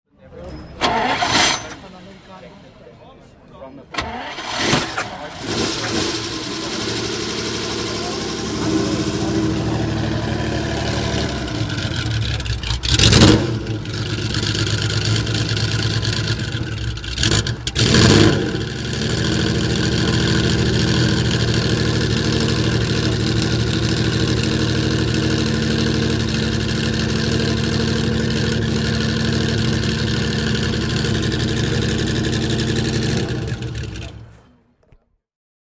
Alfa Romeo 33 Stradale - Starten